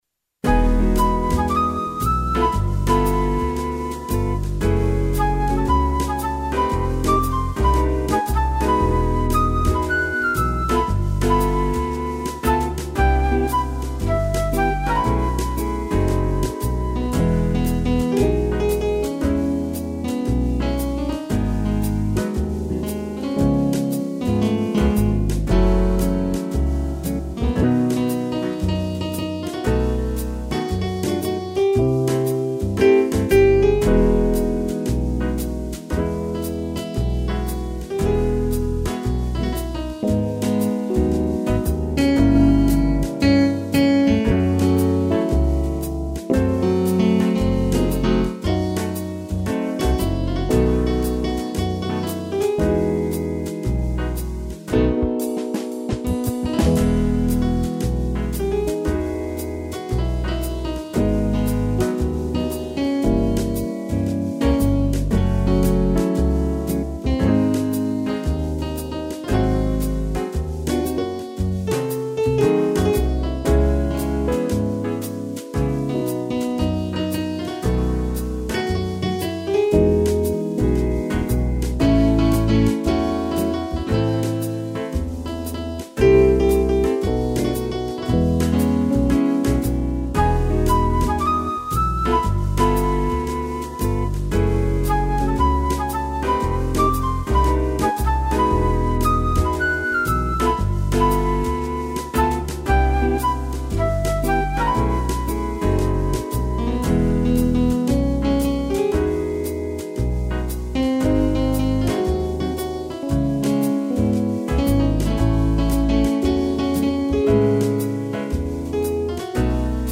(instrumental)
piano e trombone
2 pianos e trombone